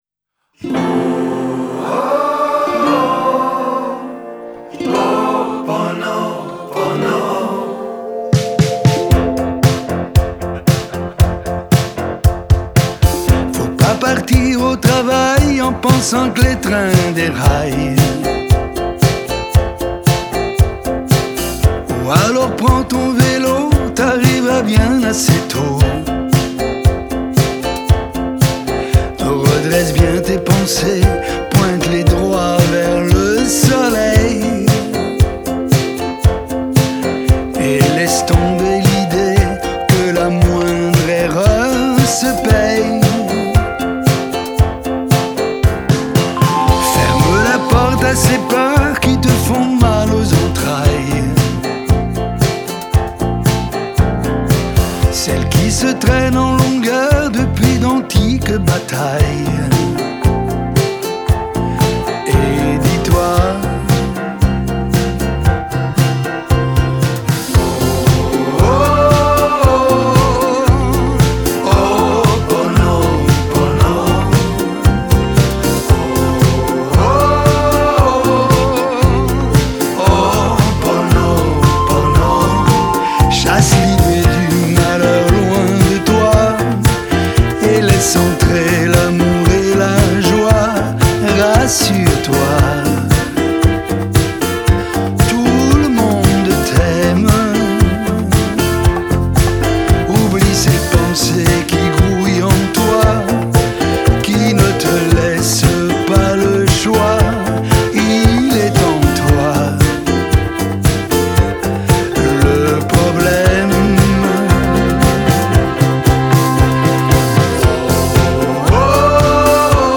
Genre: French Pop, French Chanson